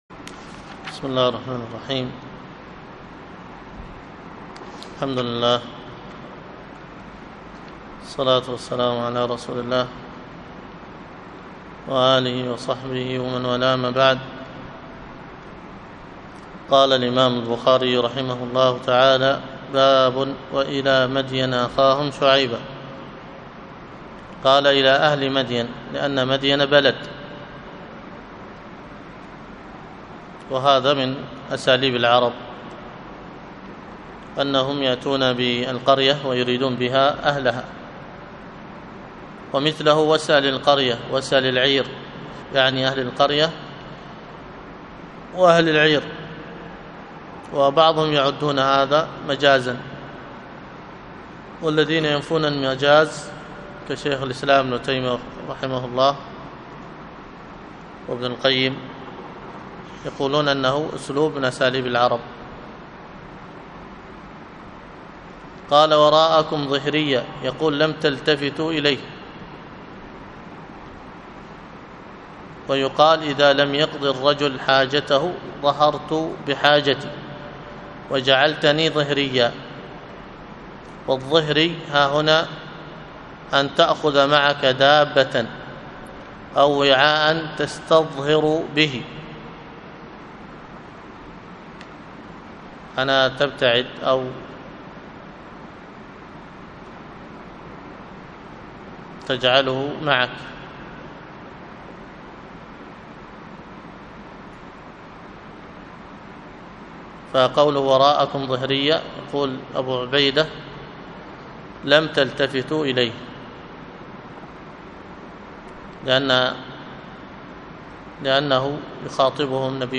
الدرس في شرح حائية ابن أبي داود 6، الدرس السادس : وفيه الثلاثة الأبيات (وقل يتجلى الله للخلق جهرة ...